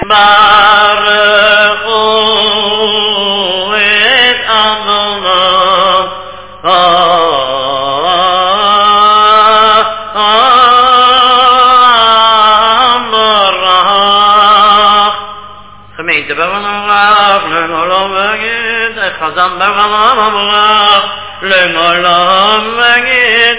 Sung by chazzan and congregant